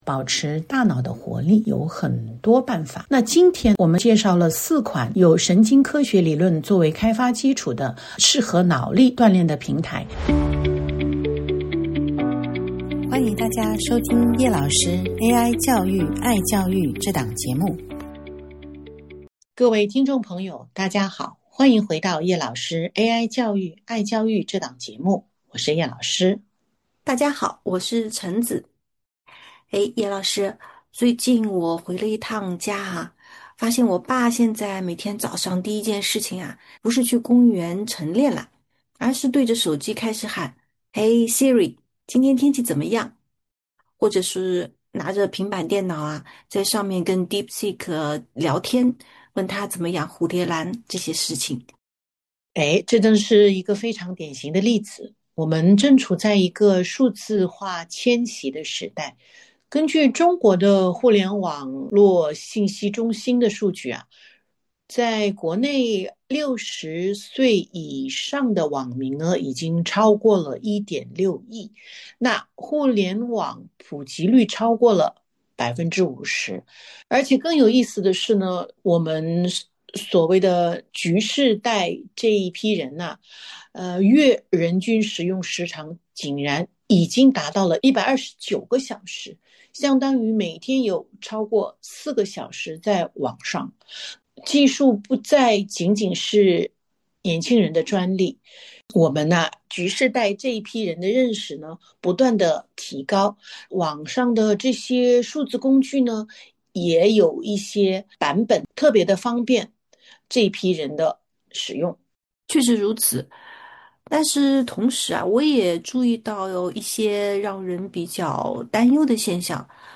这期节目，两位主持人继续和熟龄朋友们聊，关于保持大脑活力的话题。